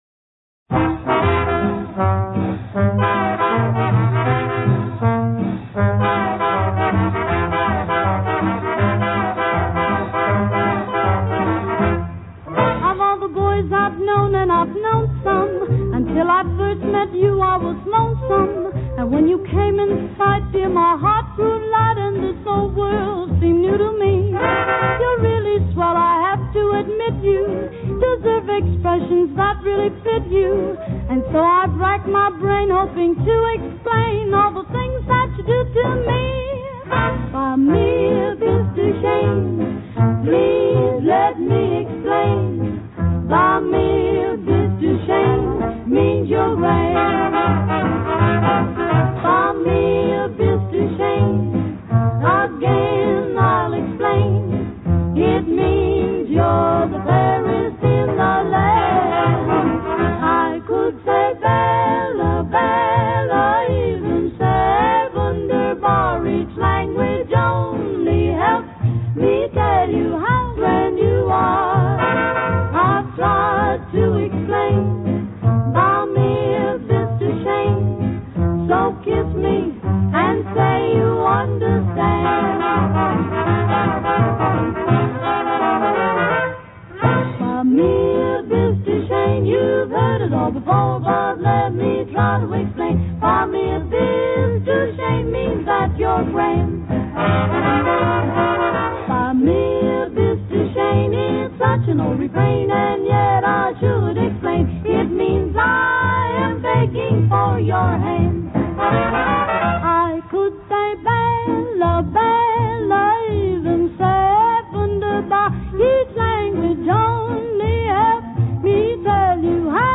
вокальным трио